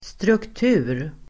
Uttal: [strukt'u:r]